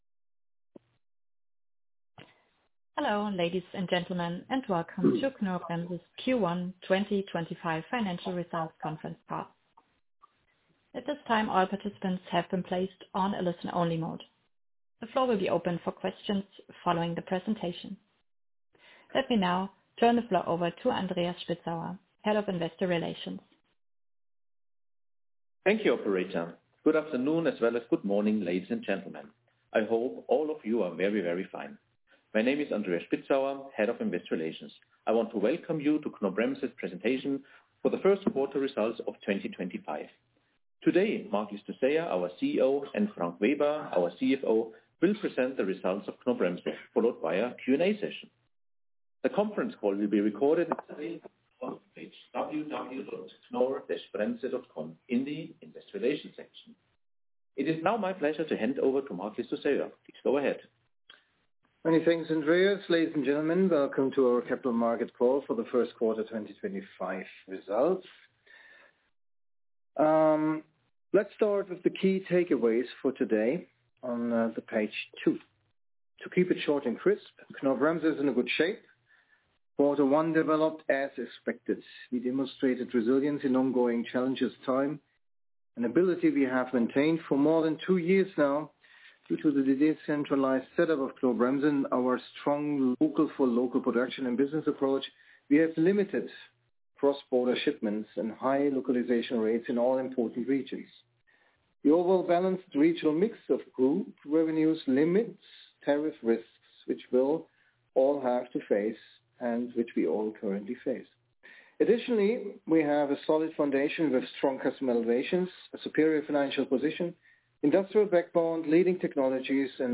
Recording of the Conference Call Q1/25 Financial Results (incl. Q&A)